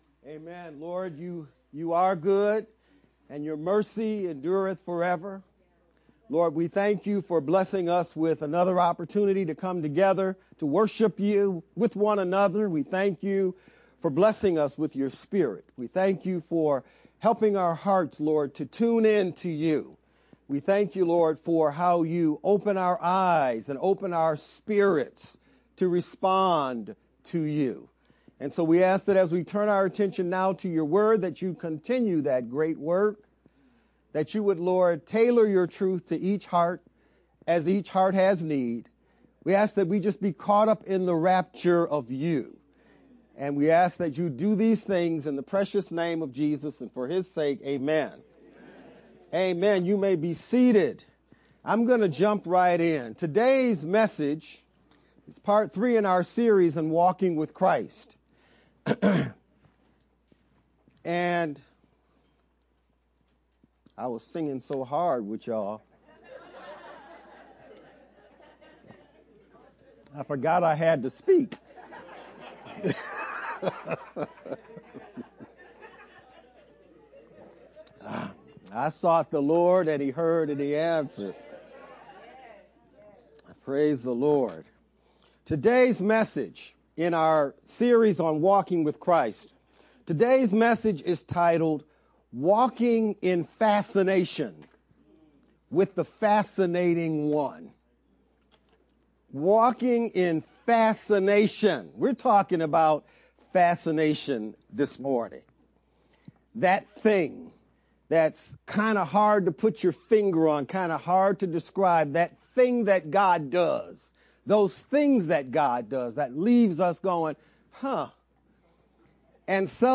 Nov-16th-VBCC-edited-sermon-only_Converted-CD.mp3